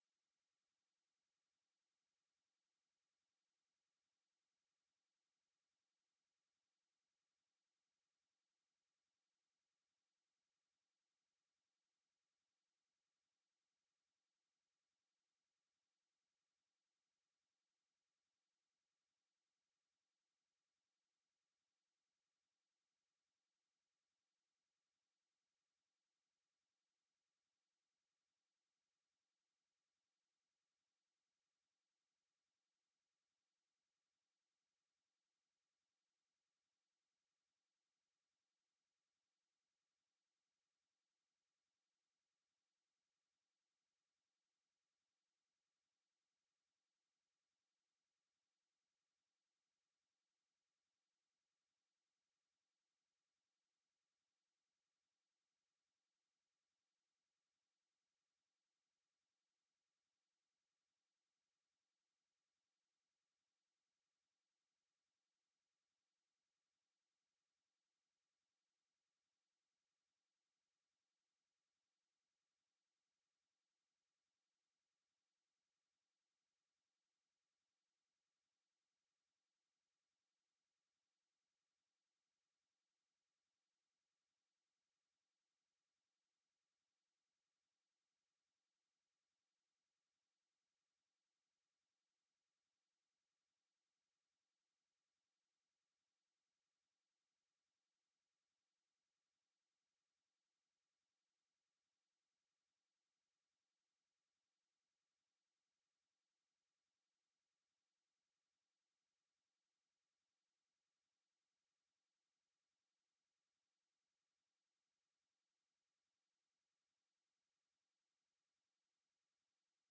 SERMONS | Sunbury City Church